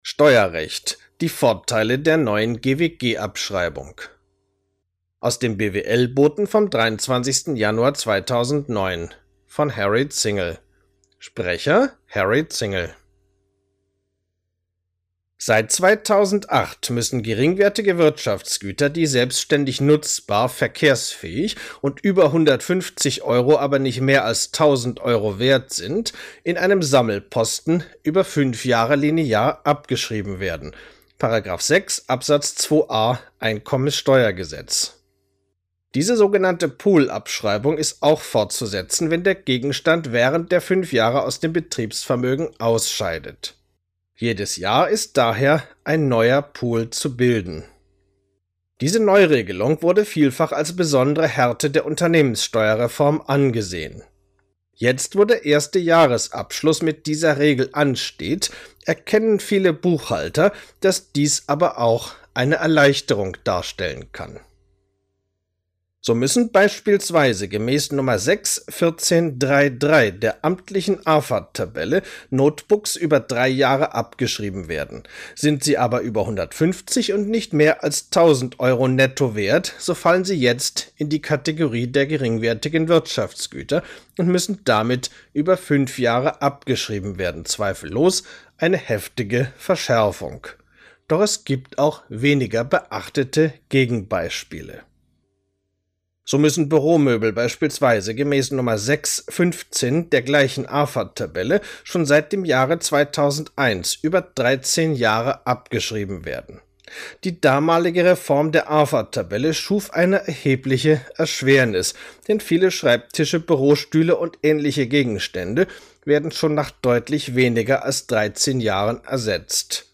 Artikel als MP3 anhören, gesprochen vom Autor: